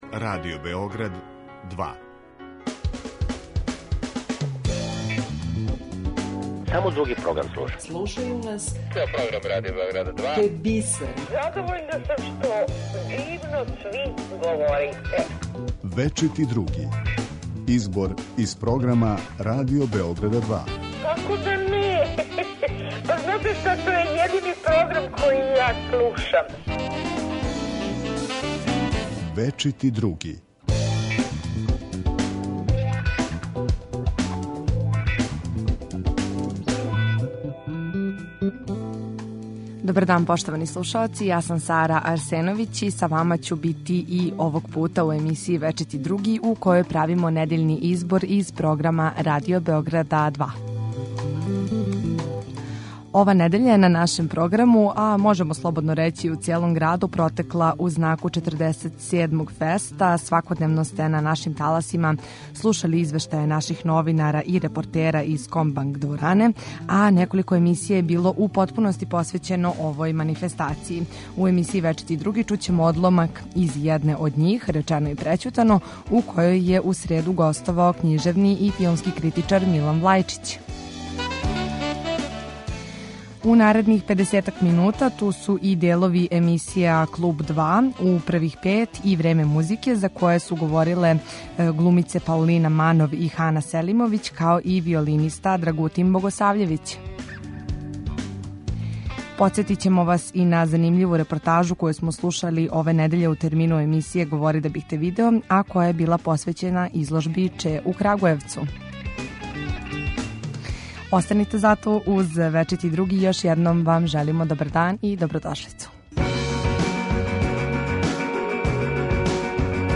Избор из програма Радио Београда 2